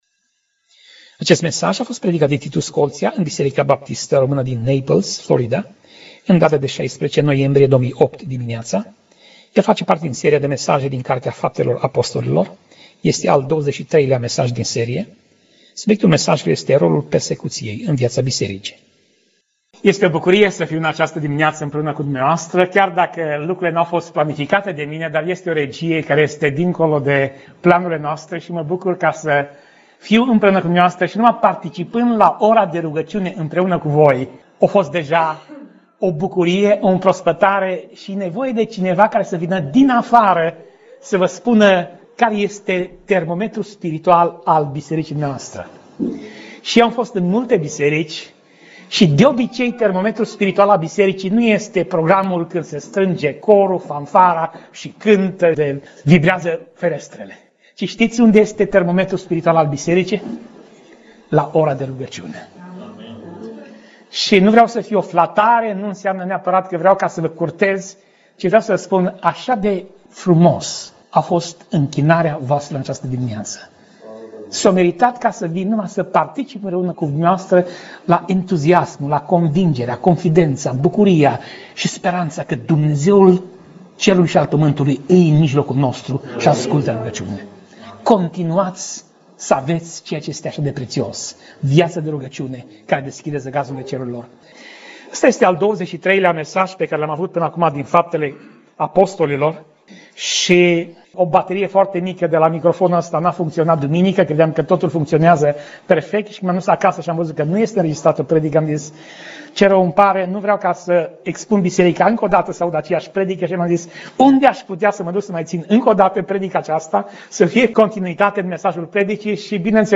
Pasaj Biblie: Faptele Apostolilor 4:1 - Faptele Apostolilor 4:22 Tip Mesaj: Predica